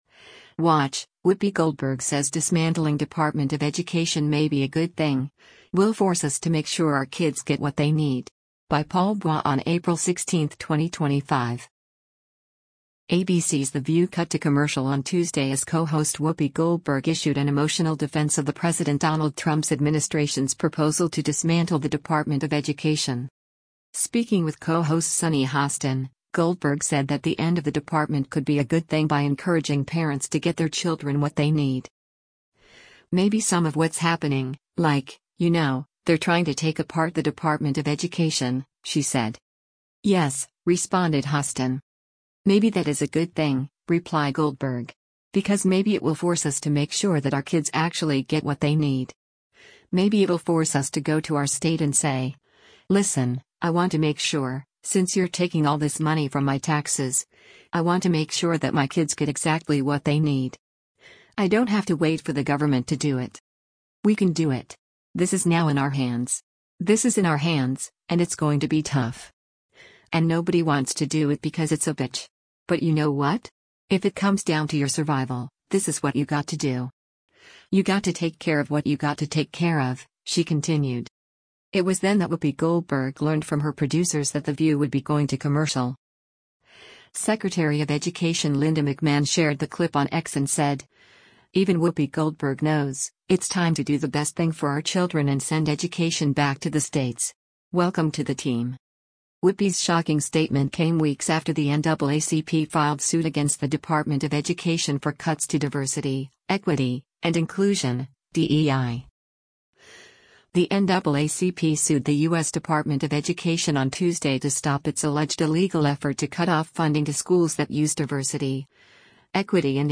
ABC’s The View cut to commercial on Tuesday as co-host Whoopi Goldberg issued an emotional defense of the President Donald Trump’s administration’s proposal to dismantle the Department of Education.
Speaking with co-host Sunny Hostin, Goldberg said that the end of the department could be a “good thing” by encouraging parents to get their children what they need.